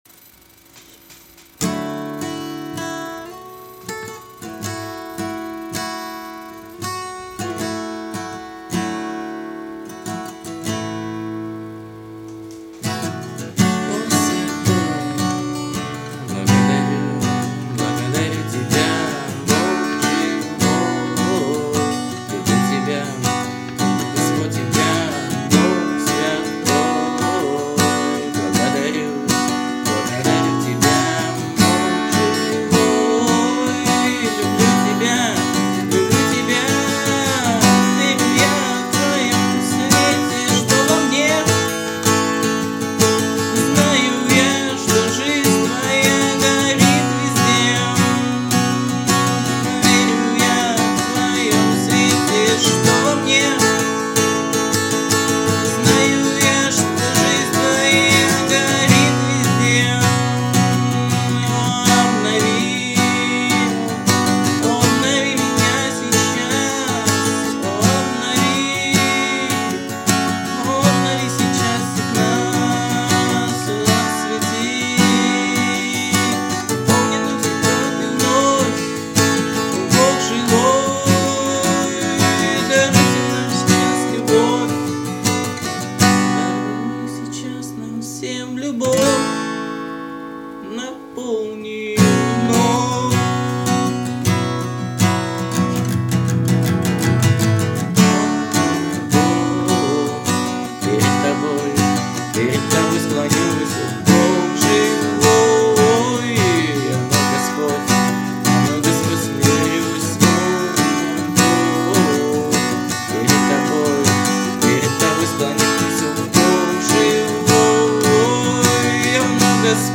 208 просмотров 94 прослушивания 2 скачивания BPM: 82